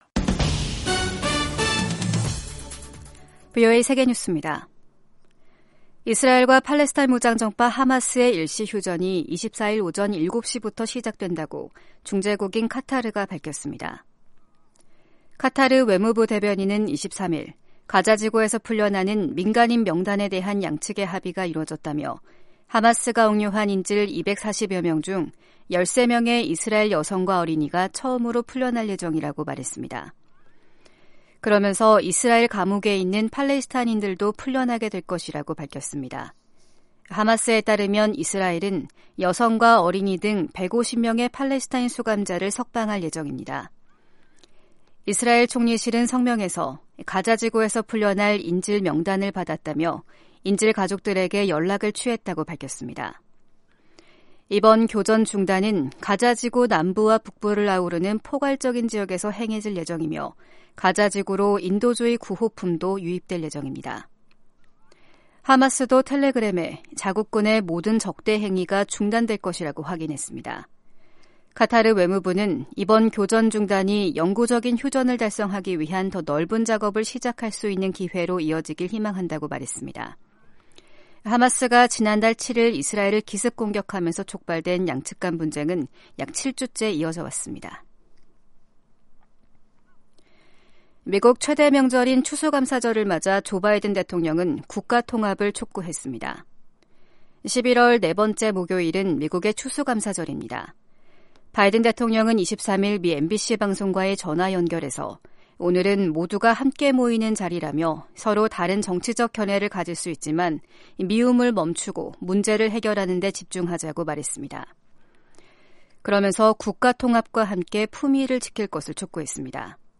세계 뉴스와 함께 미국의 모든 것을 소개하는 '생방송 여기는 워싱턴입니다', 2023년 11월 24일 아침 방송입니다. '지구촌 오늘'에서는 나렌드라 모디 인도 총리가 팔레스타인 가자지구 내 휴전 합의를 환영한다고 주요20개국(G20) 화상 정상회의에서 밝힌 소식 전해드리고, '아메리카 나우'에서는 재선에 도전하는 조 바이든 대통령에게 고령에 따른 건강 문제와 국내 경제, 외교 등 풀어내야 할 과제가 산적해 있다는 분석 살펴보겠습니다.